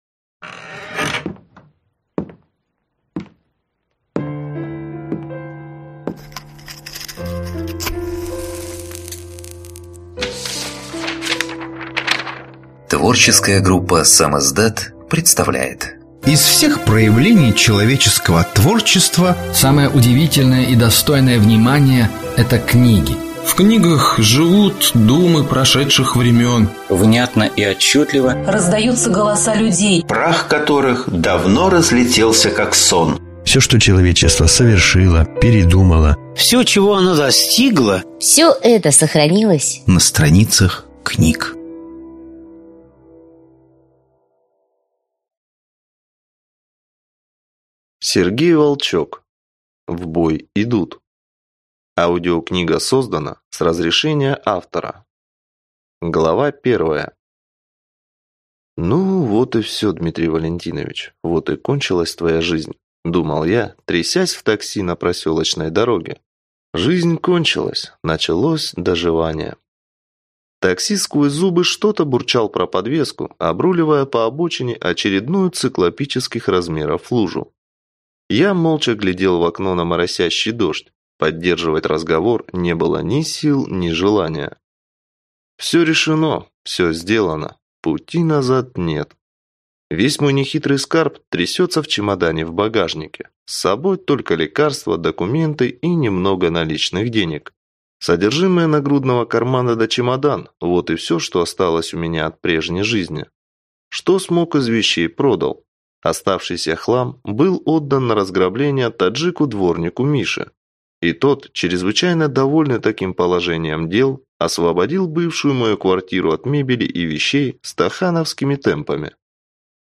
Аудиокнига В бой идут… Книга первая | Библиотека аудиокниг